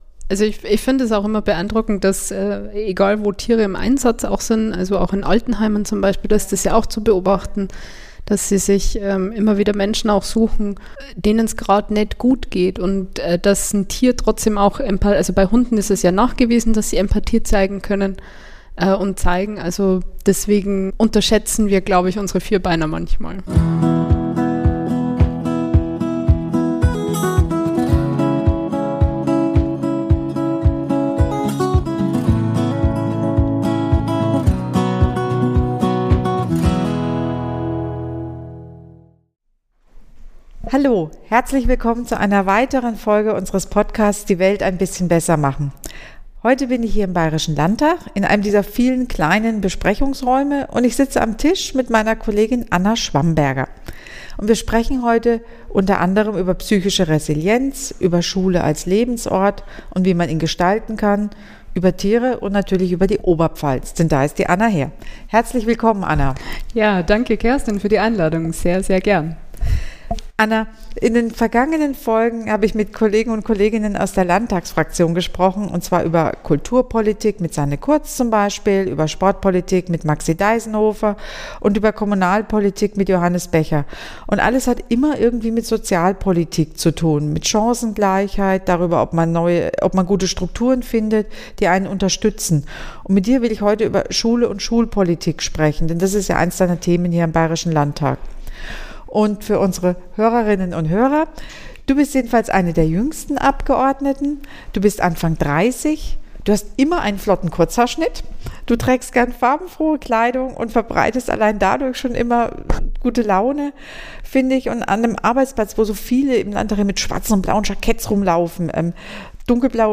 Im Gespräch mit Landtagskollegin und Moderatorin Kerstin Celina berichtet sie also von ihren ganz eigenen Erfahrungen, ihren Ideen für eine glückliche Schulzeit und auch ihrer Liebe zu Tieren.